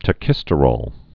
(tə-kĭstə-rôl, -rōl)